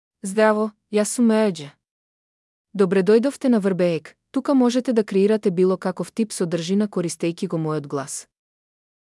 Marija — Female Macedonian AI voice
Marija is a female AI voice for Macedonian (North Macedonia).
Voice sample
Listen to Marija's female Macedonian voice.
Female